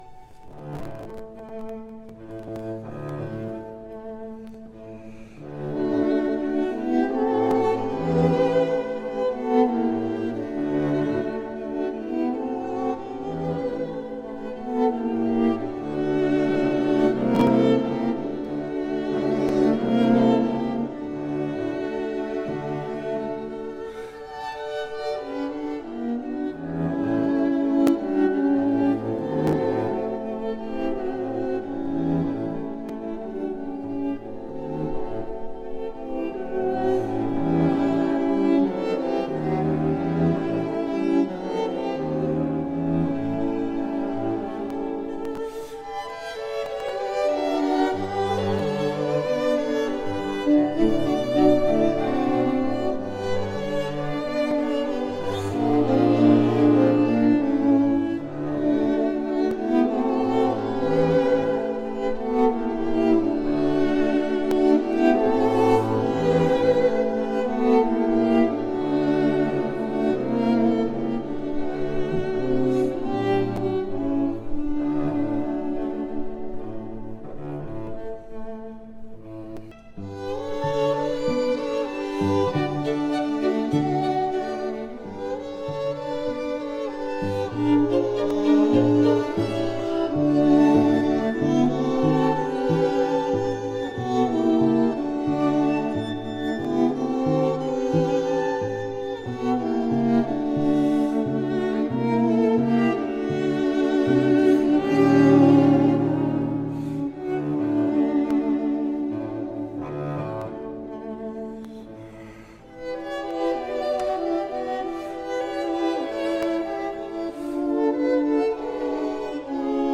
(string qtet)
edited with cuts